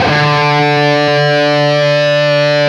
LEAD D 2 CUT.wav